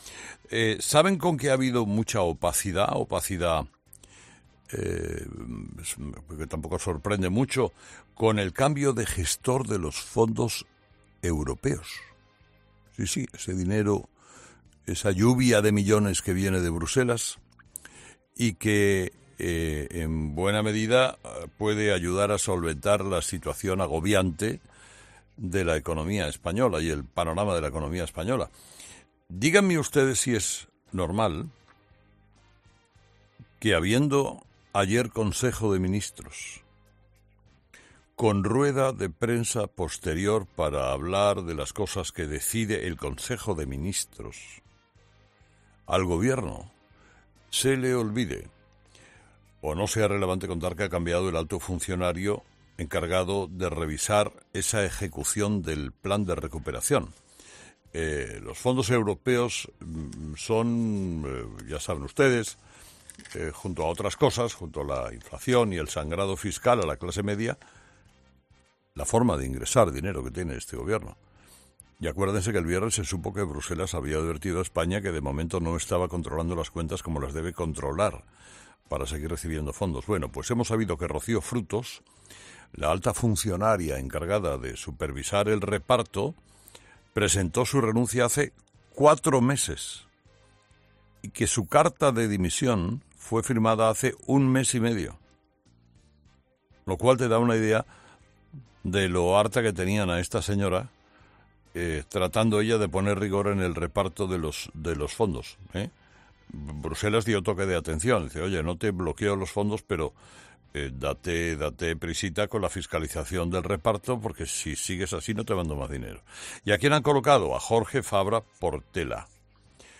Escucha el comentario de Carlos Herrera sobre el relevo del supervisor del reparto de los fondos europeos